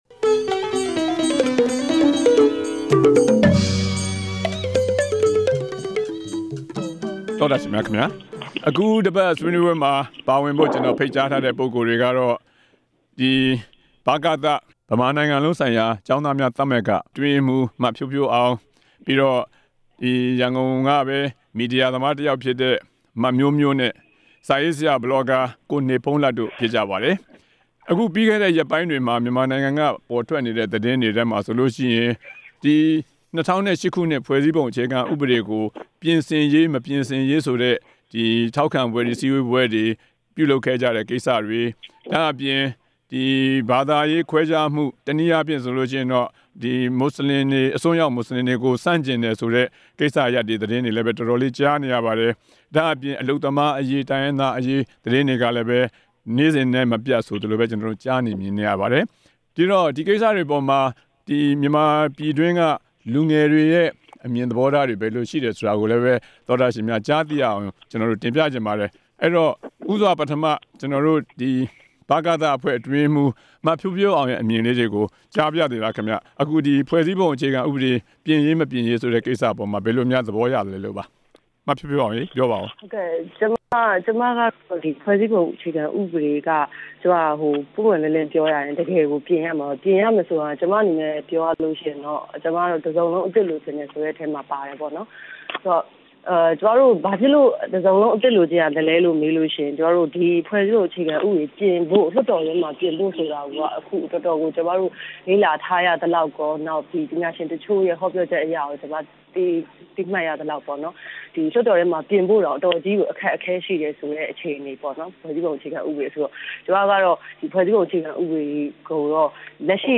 ဆွေးနွေးပွဲ စကားဝိုင်း
လူငယ်ခေါင်းဆောင် နှစ်ဦး၊ မီဒီယာသမား တစ်ဦးတို့ရဲ့ ရှုမြင်သုံးသပ်ချက်တွေနဲ့အတူ